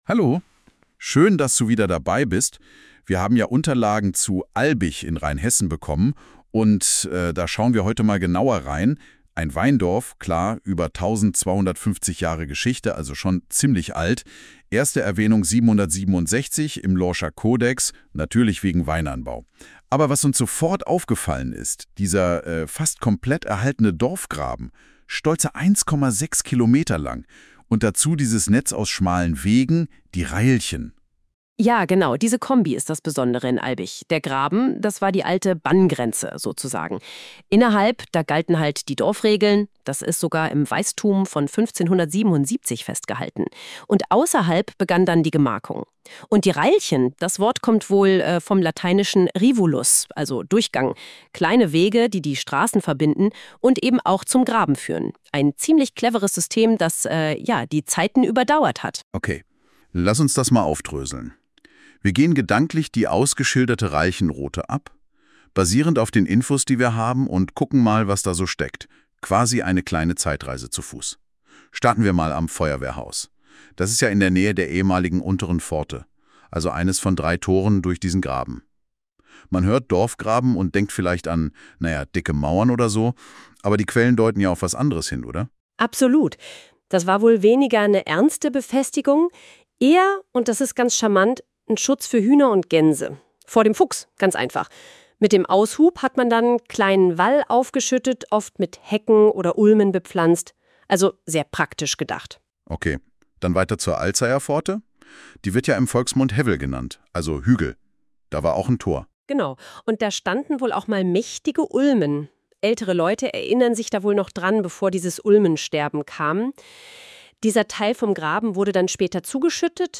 Audioguide Reilchen Route
Albig-Church_-A-Millennium-of-Faith-and-Bells.mp3